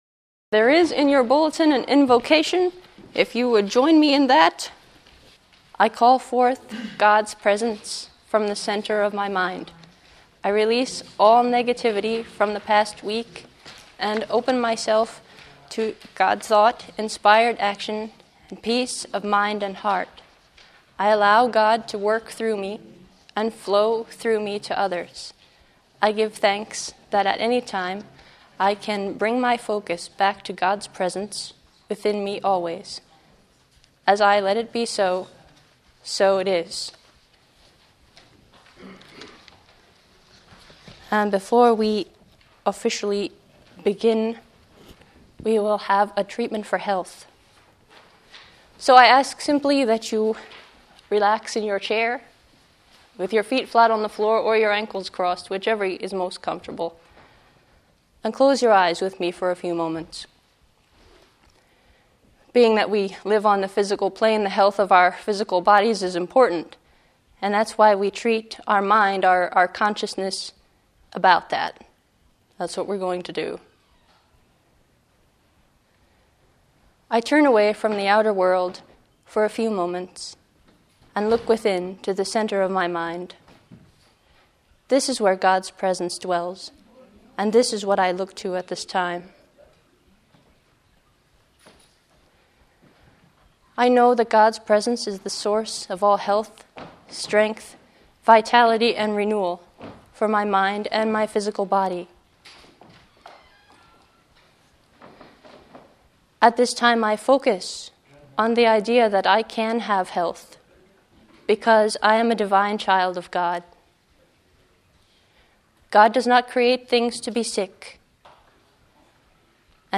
do-not-worry_service.mp3